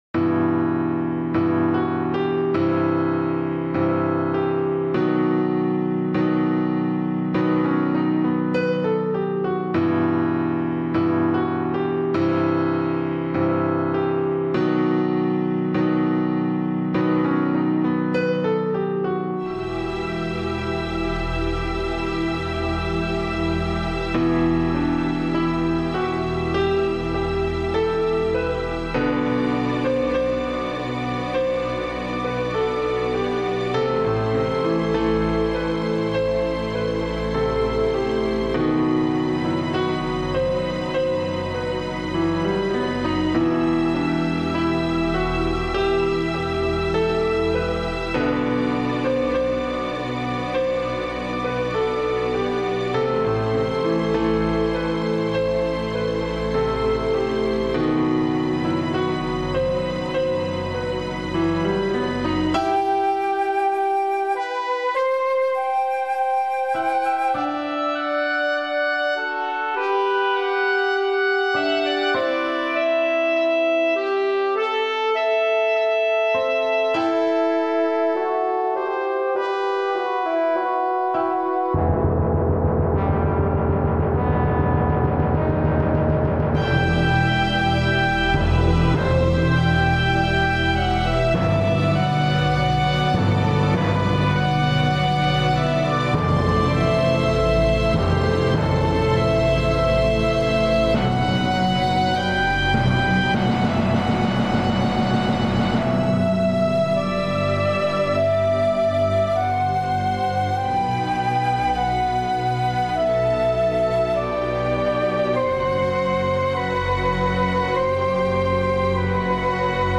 genre:orchestral